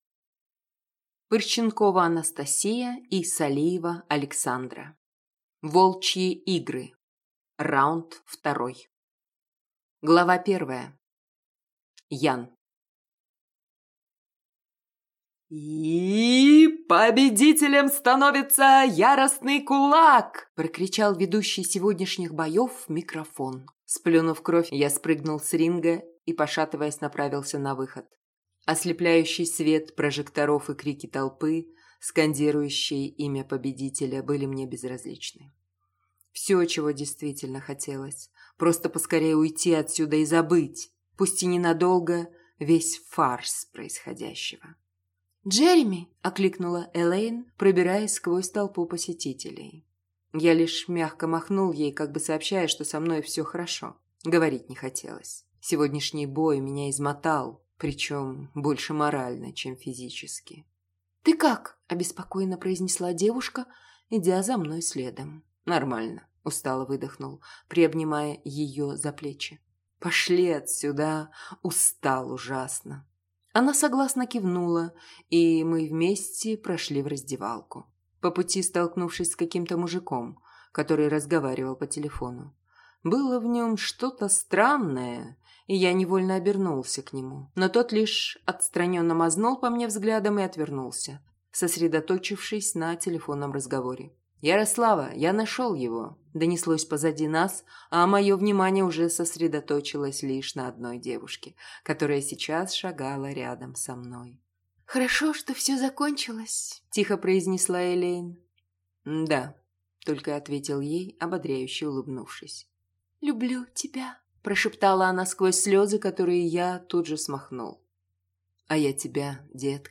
Аудиокнига Волчьи игры. Раунд 2 | Библиотека аудиокниг